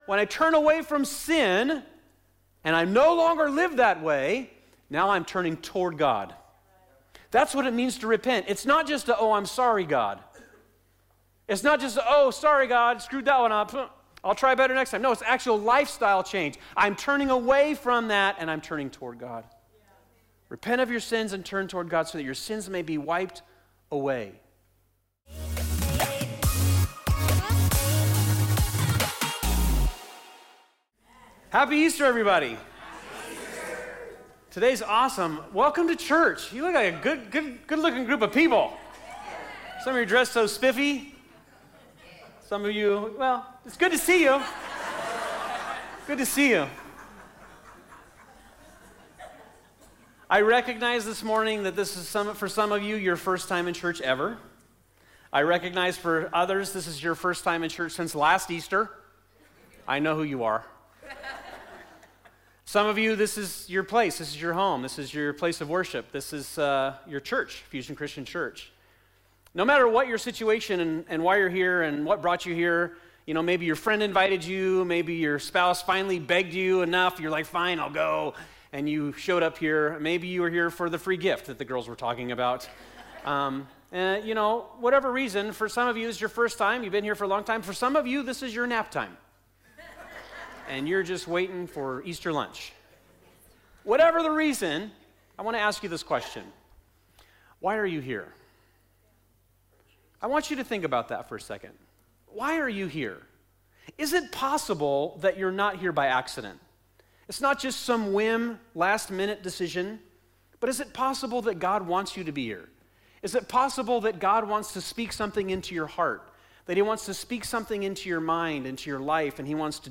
Easter-Sunday_2025.mp3